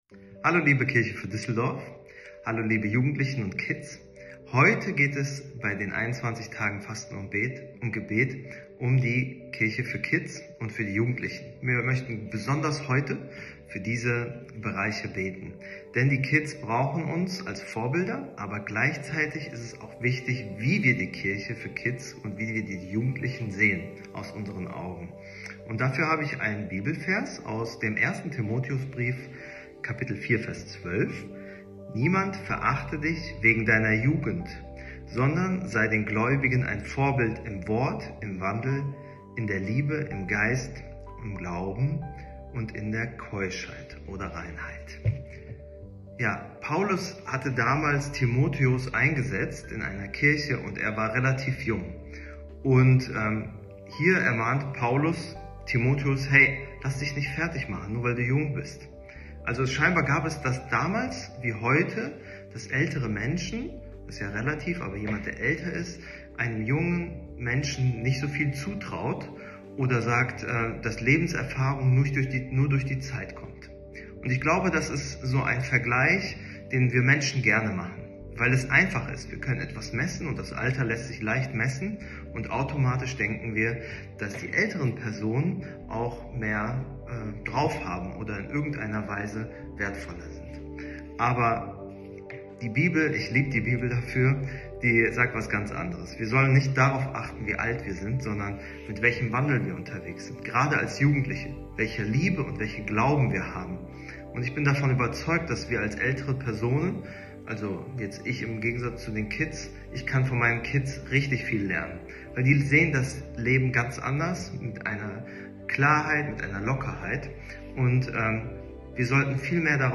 Tag 15 der Andacht zu unseren 21 Tagen Fasten & Gebet